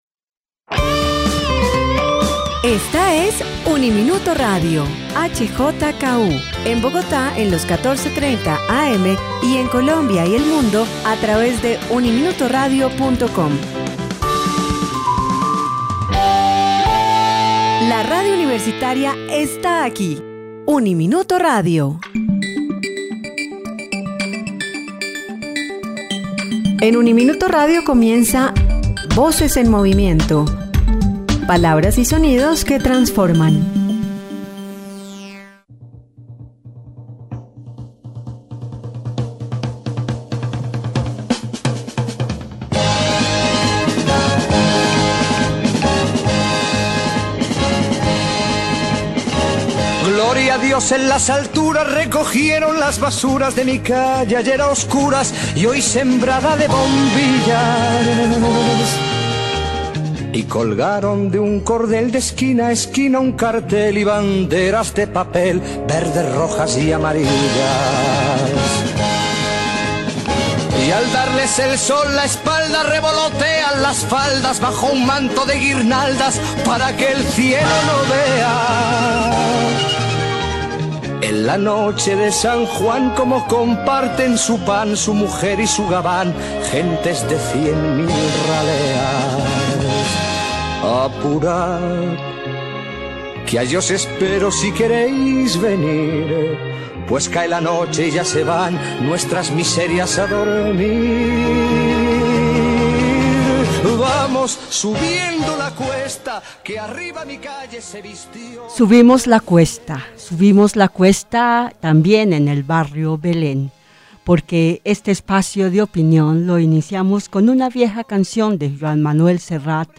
Para conversar sobre el tema, Espacio de Opinión invitó a tres habitantes del barrio